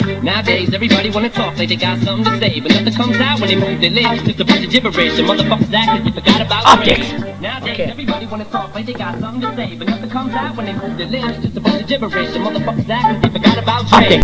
MIXES